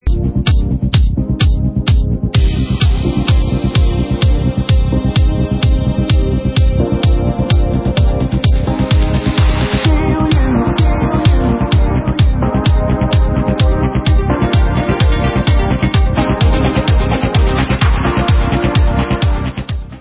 Extended Original
sledovat novinky v kategorii Pop